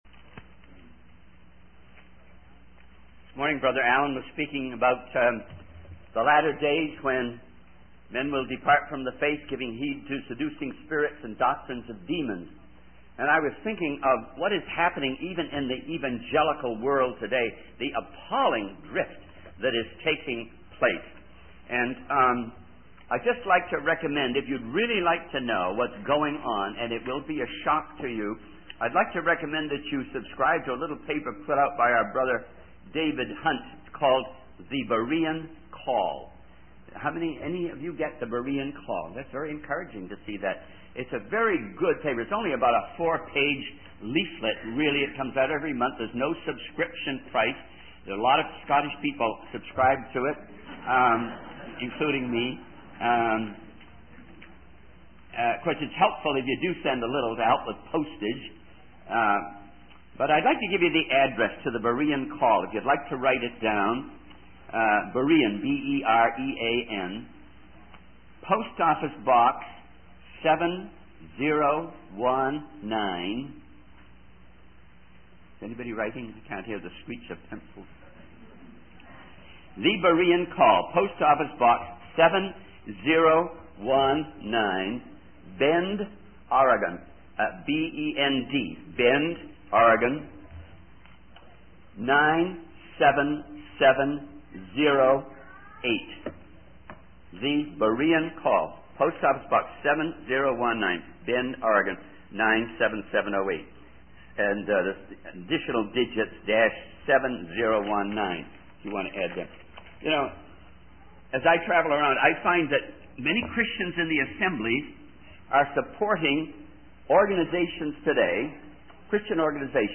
In this sermon, the preacher reflects on the story of Abraham and Isaac from the Bible. He describes the intense moment when Abraham is ready to sacrifice his son Isaac as an act of obedience to God.